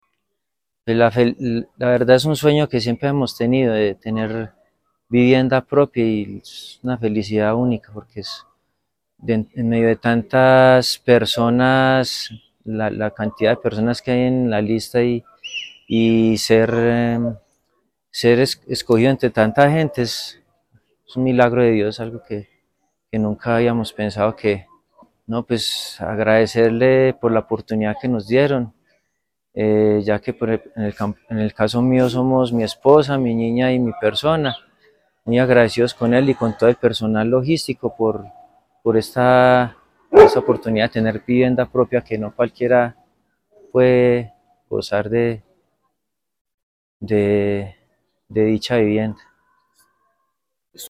beneficiario.